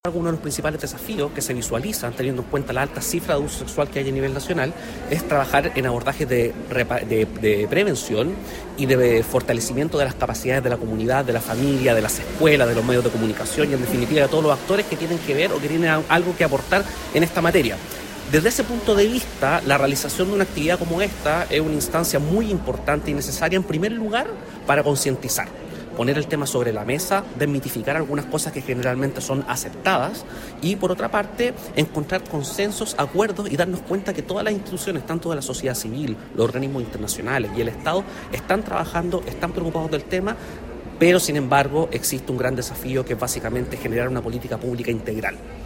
En Castro se realizó el seminario denominado “El Peor Abuso”